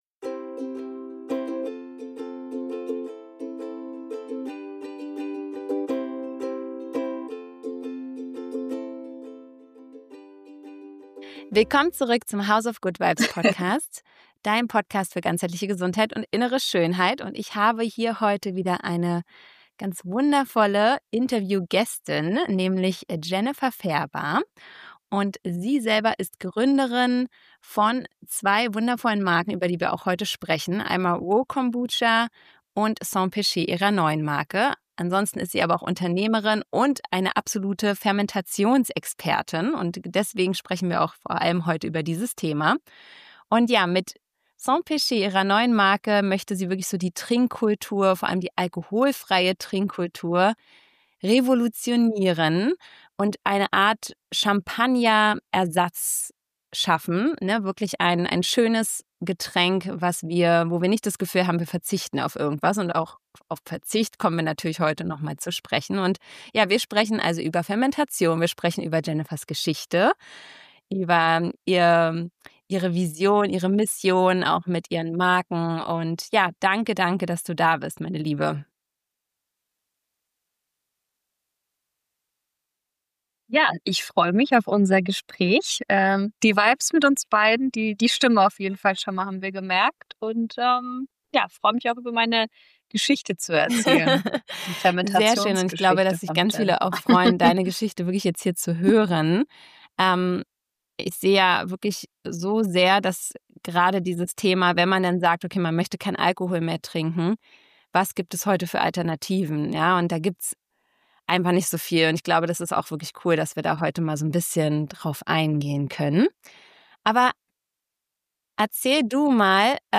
Ein Gespräch über Genuss ohne Rausch, über die Magie der Fermentation und...